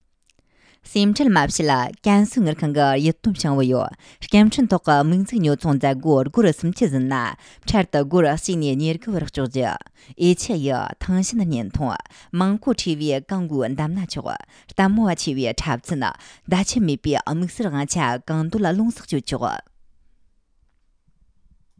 安多藏语-推荐